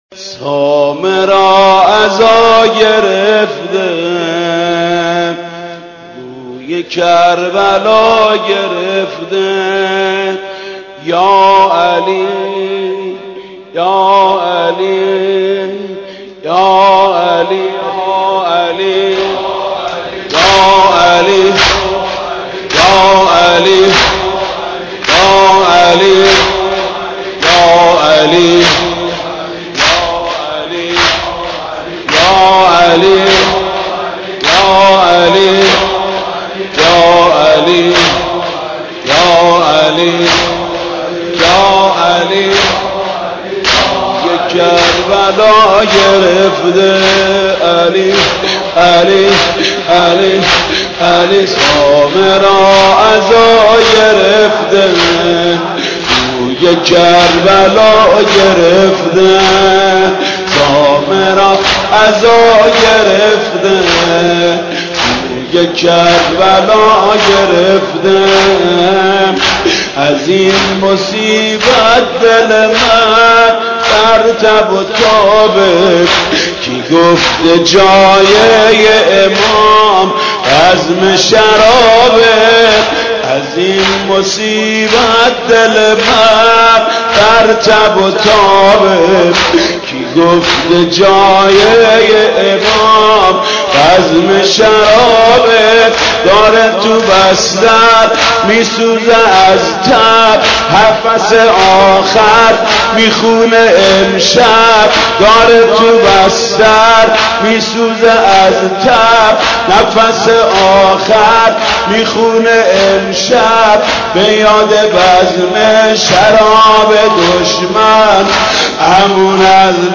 مداحی حاج محمود کریمی در عزای امام هادی (ع)